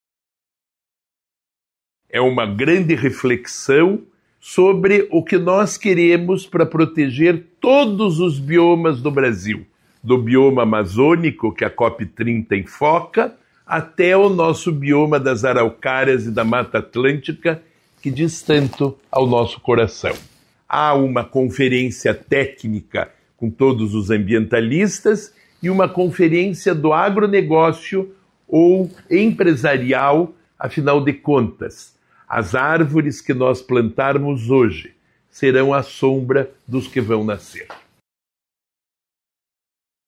O secretário de estado de Desenvolvimento Sustentável, Rafael Greca, falou da importância do evento.